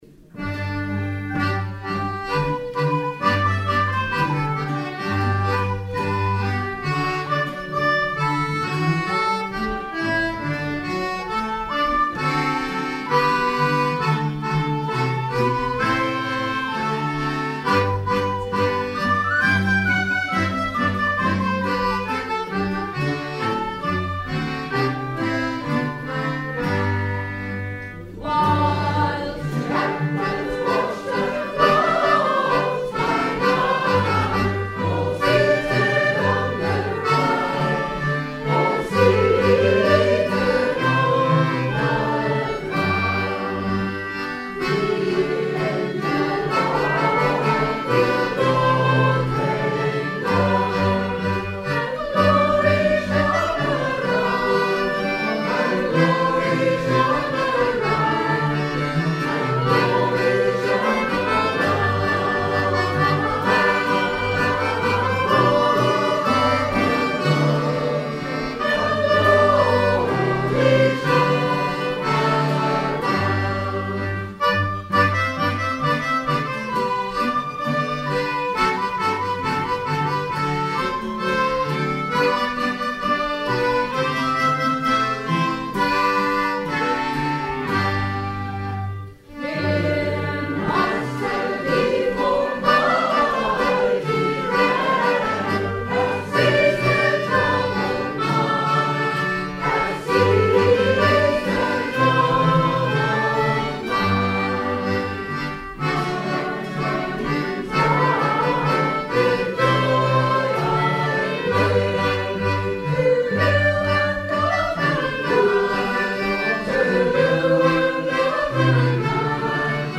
11 December 2024 : Carols at the Institute
This year, to make up for not going out and singing on the streets, we met for a general sing on the morning of 11 December, at the Institute, including instruments and members of the recently-formed Sacred Harp Singing group.
Instruments: fiddle, octave mandolin, whistle, recorder, concertinas, cello.